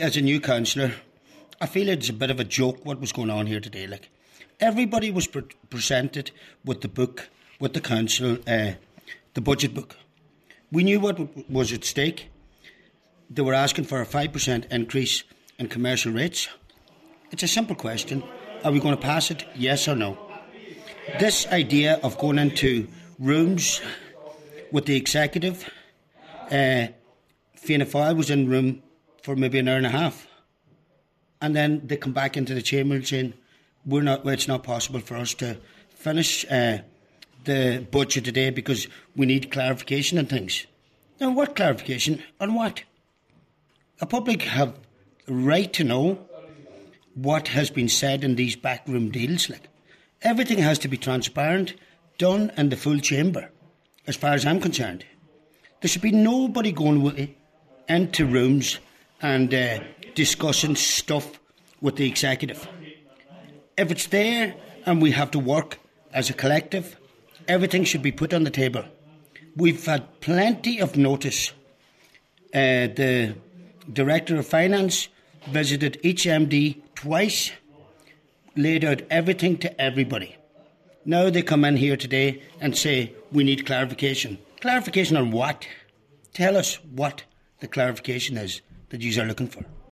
Cllr Dennis McGee hit out at what he called secret meetings between Fianna Fail and senior officials.